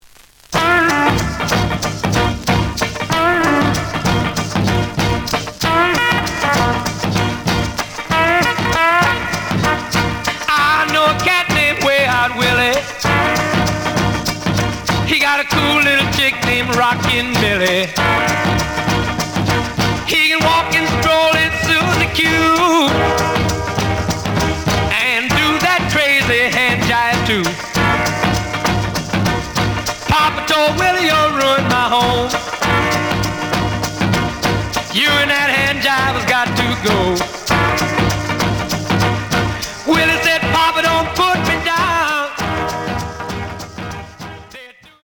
試聴は実際のレコードから録音しています。
●Genre: Rhythm And Blues / Rock 'n' Roll
盤に若干の歪み。